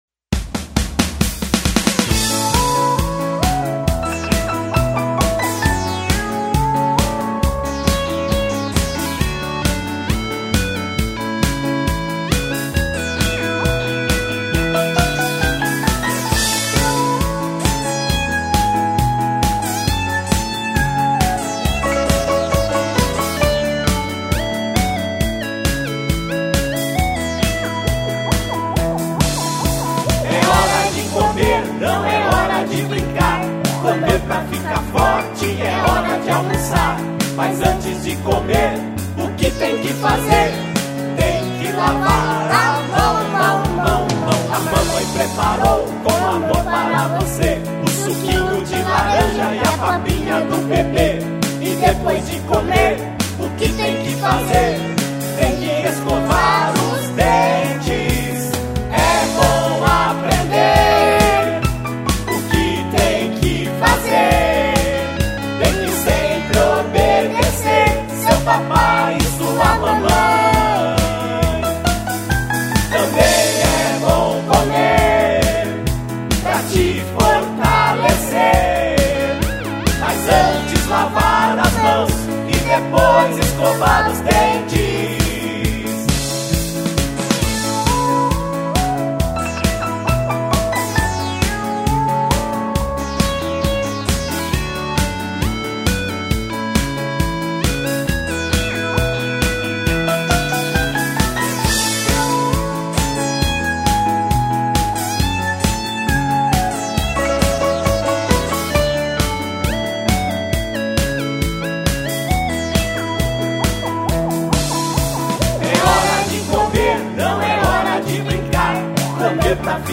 EstiloInfantil